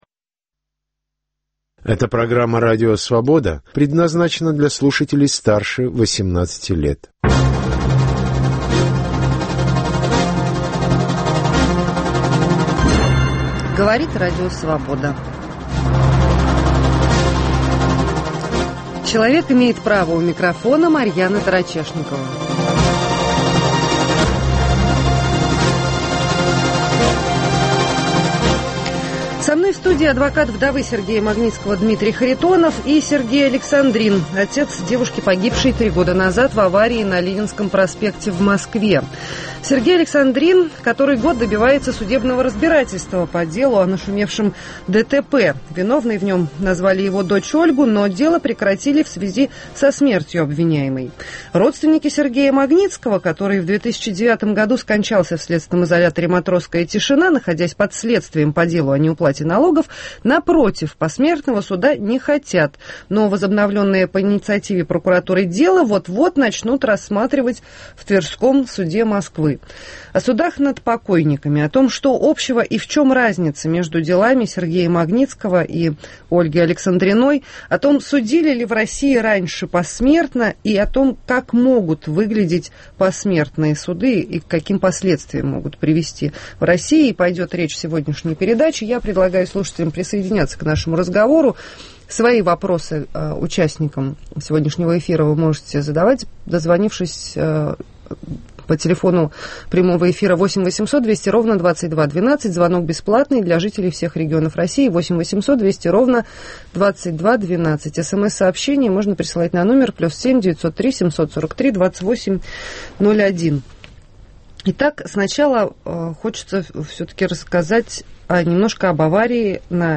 О правах и свободах говорят правозащитники, политики, экономисты, деятели науки и культуры, обеспокоенные состоянием дел с правами человека в России, а также граждане, пострадавшие от произвола властей.